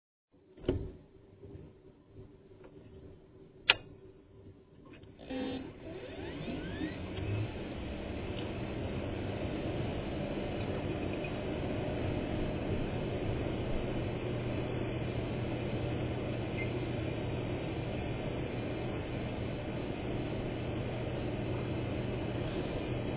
incase u were wondering what it sounds like to be inside my server when i turn it on.